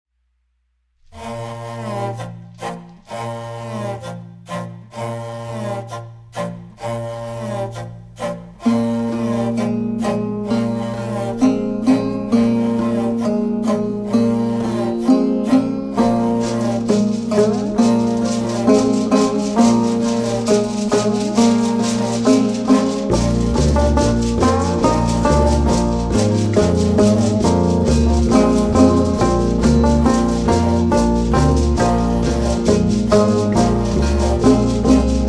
voice (khai in kharygha style), yykh (fiddle)
chatkhan (wooden box zither)
timîr-khomys (jew‘s harp), tüür (frame drum, shaman's drum)